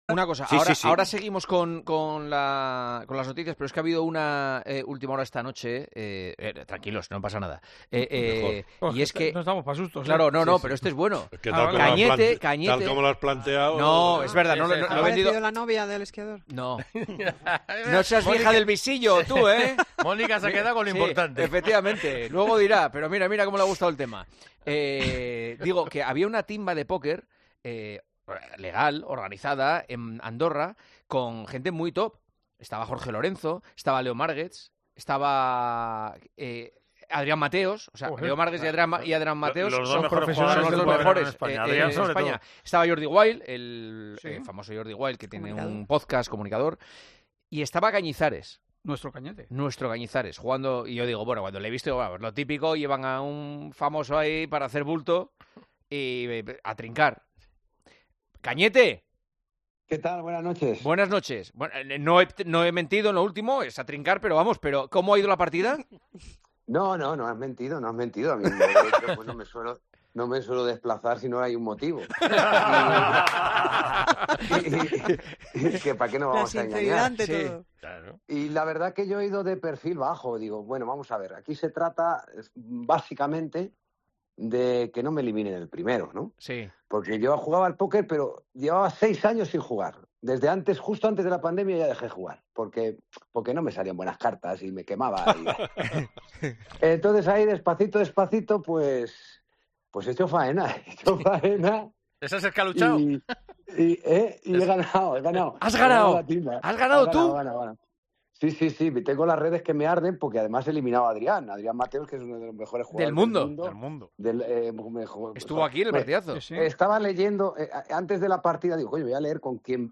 Juanma Castaño charla con Santi Cañizares sobre el torneo de póker que ha ganado